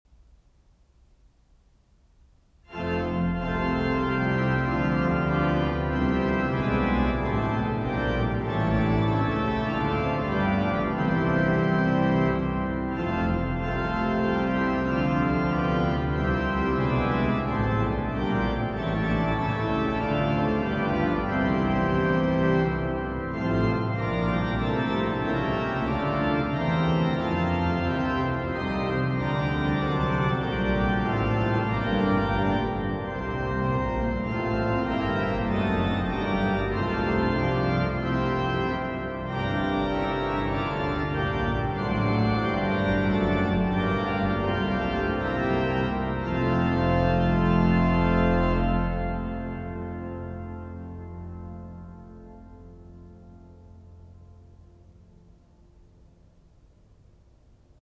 Mottolied_-_Orgelsatz.mp3